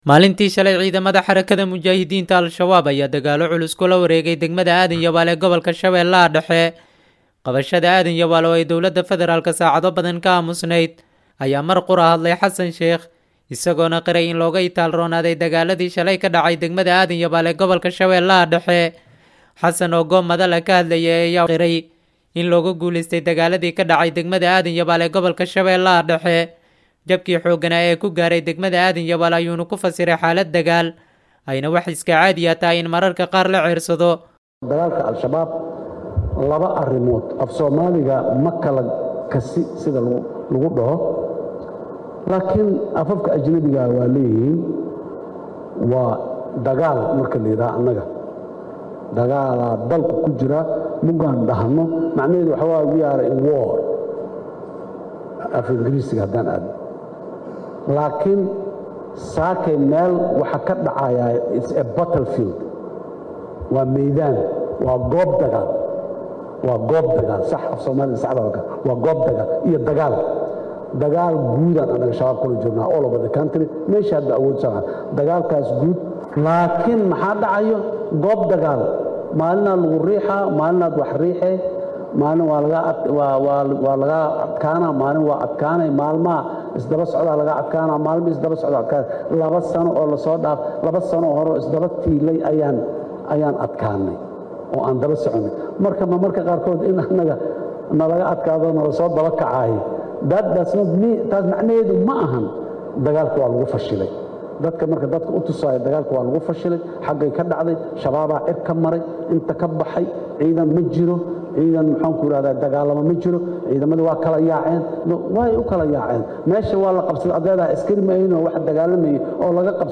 Xasan oo goob madal ah ka hadlayay ayaa waxa qiray, in looga guuleystay dagaalladii ka dhacay degmada Aadan Yabaal ee gobalka Sh-dhexe.
Halkaan ka Degso Warka Xasan Sheekh